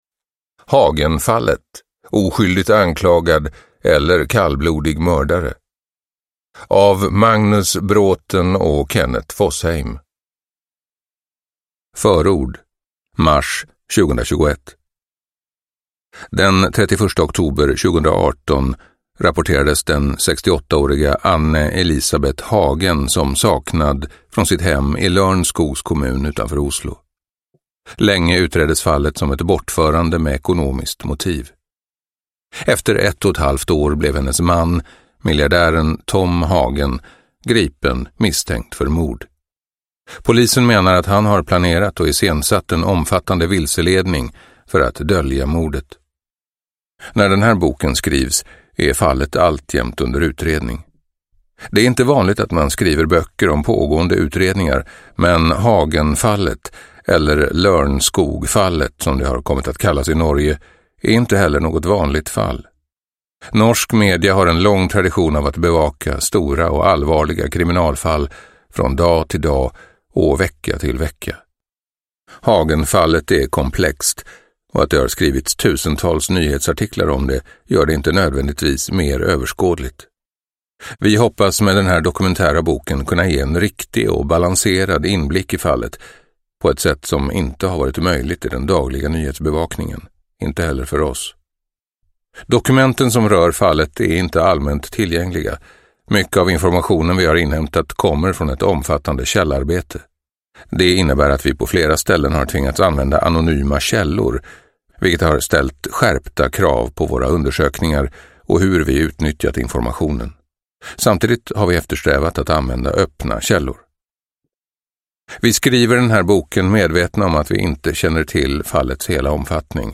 – Ljudbok – Laddas ner